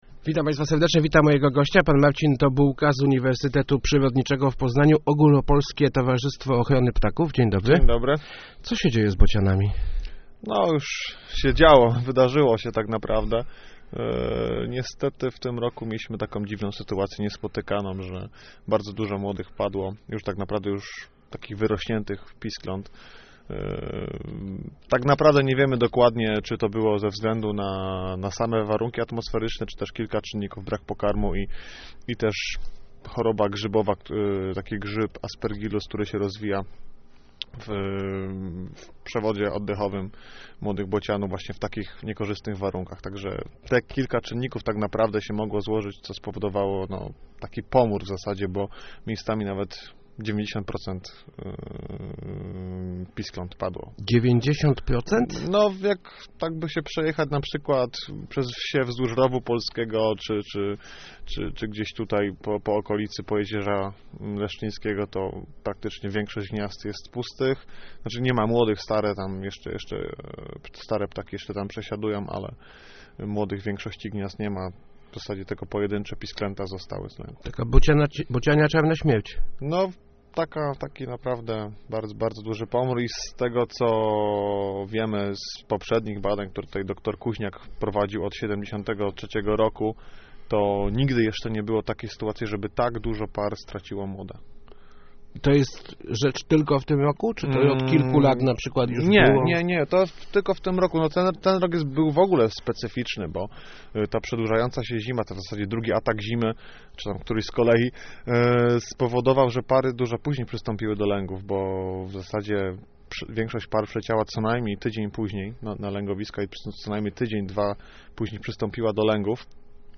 Start arrow Rozmowy Elki arrow Bociania hekatomba